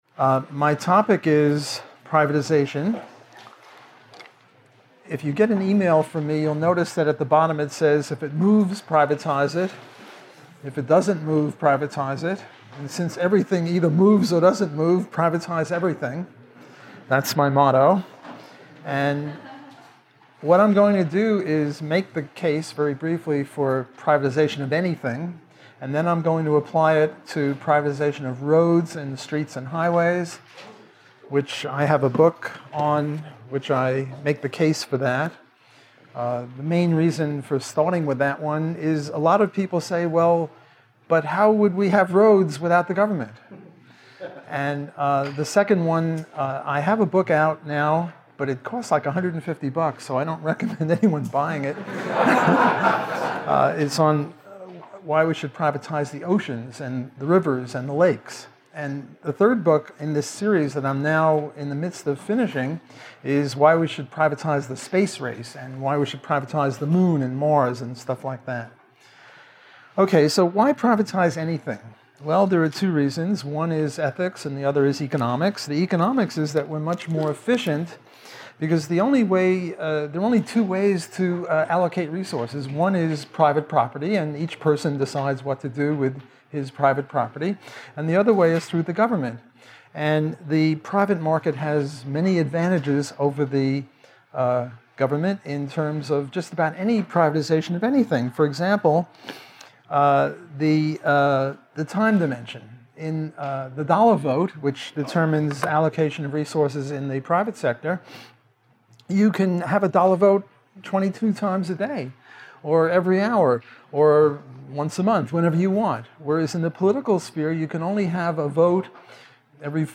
Recorded at the Mises Institute in Auburn, Alabama, on 28 July 2016.